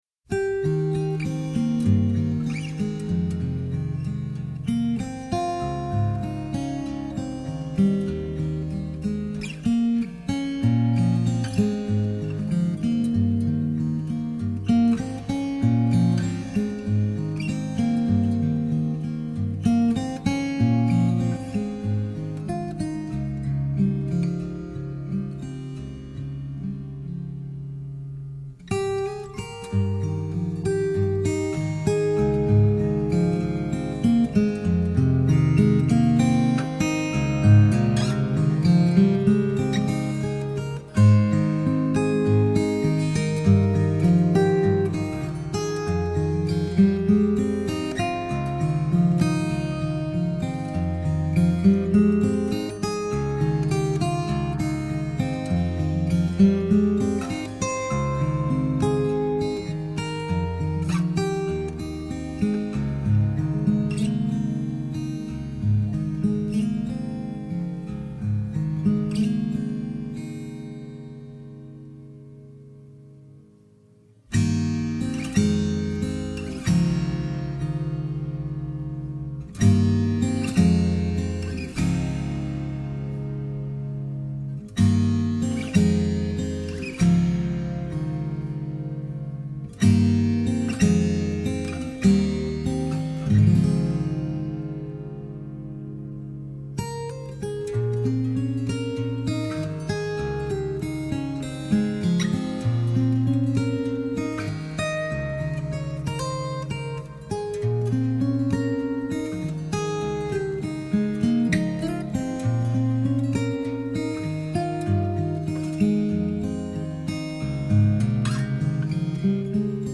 音乐风格: New Age / Instrumental / Guitar